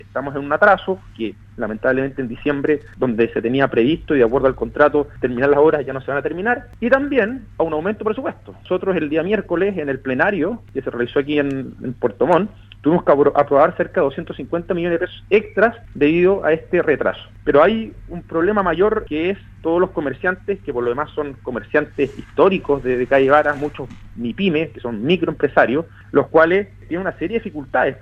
En conversación con radio Sago, el Core por la provincia de Llanquihue y presidente de la comisión de infraestructura del Consejo Regional de Los Lagos, Rodrigo Wainraihgt, se refirió a la situación que actualmente afecta a los locatarios del comercio establecido de esa importante vía de la capital regional y que recordemos según informaron, deberán cerrar debido al impacto negativo que provoca el retraso de las obras. Para el Core Wainraihgt el municipio local podría implementar un plan de mitigación que considere entre otras cosas la excepción del pago de patentes municipales para los comerciantes afectados.